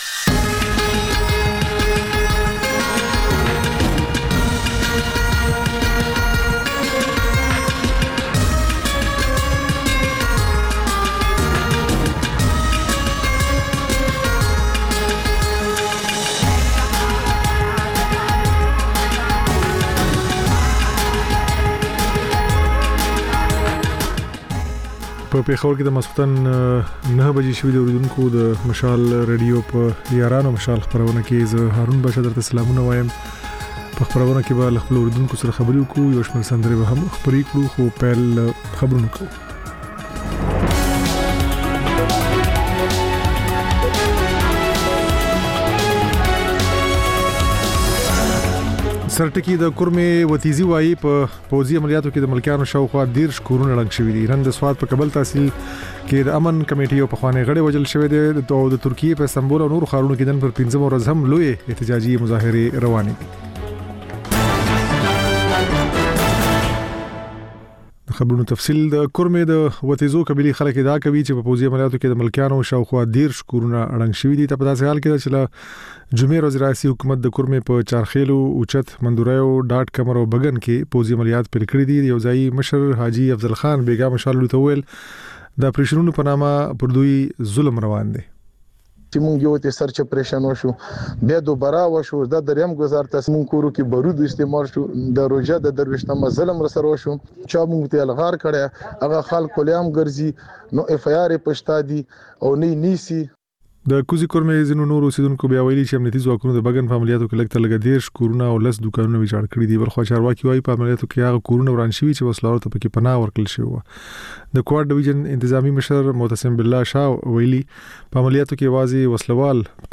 د یارانو مشال په ژوندۍ خپرونه کې له اورېدونکو سره بنډار لرو او سندرې خپروو. دا یو ساعته خپرونه هره ورځ د پېښور پر وخت د ماخوستن له نهو او د کابل پر اته نیمو بجو خپرېږي.